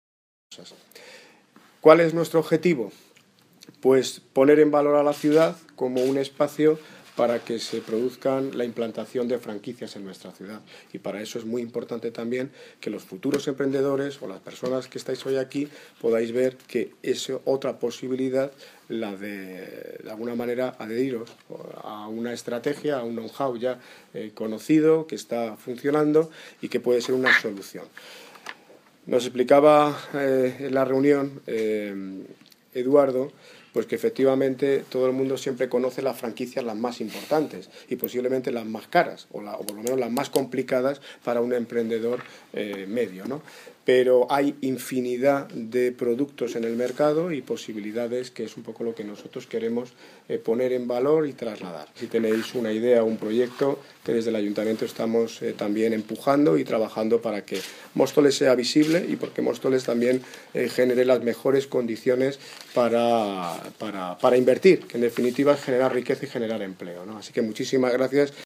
Audio Daniel Ortiz (Alcalde de Móstoles) Sobre Jornadas Informativas Franquicias